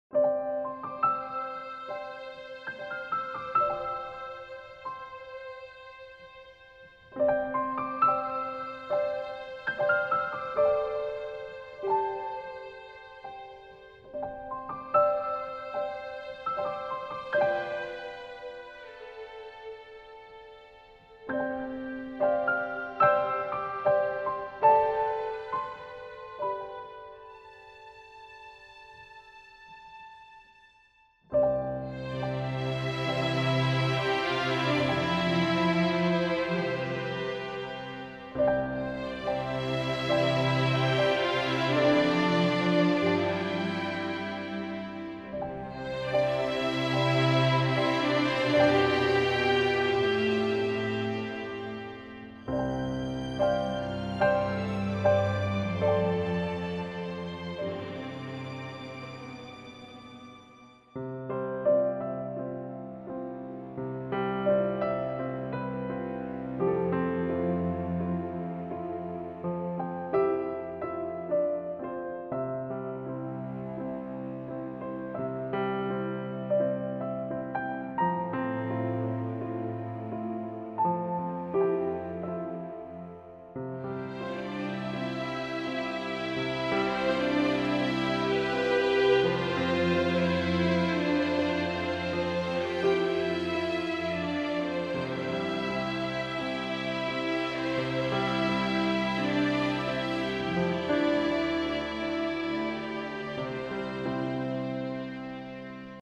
Genre: Drama, Emotional, Romance.